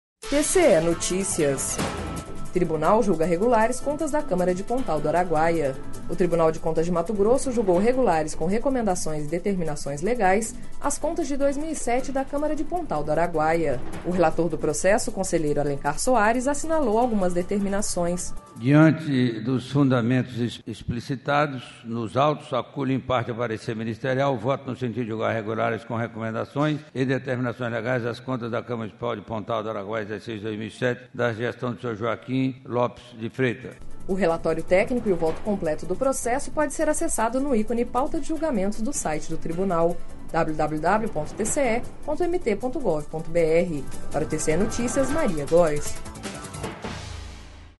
Sonora: Alencar Soares - conselheiro do TCE